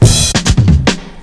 FILL 10   -L.wav